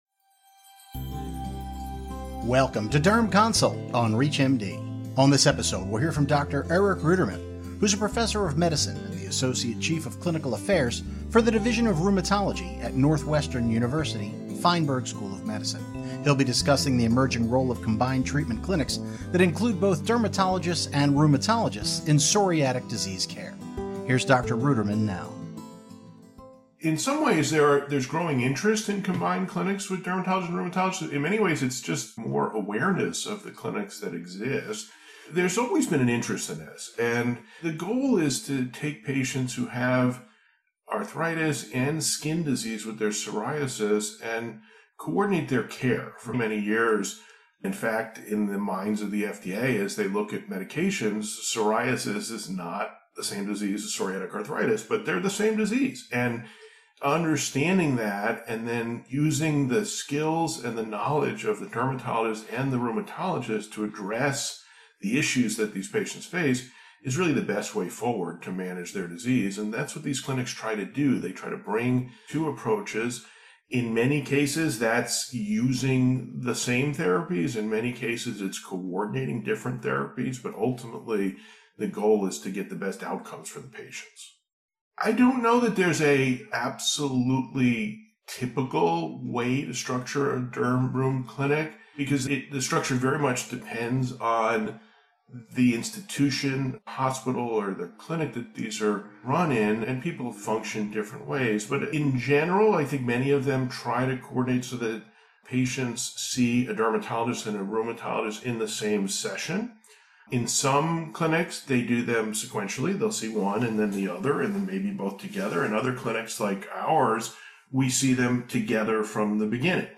ReachMD brings you the latest research, announcements, and conversations from the most important medical conferences around the world. Join us on the conference floor with keynote speakers, experts, and opinion leaders.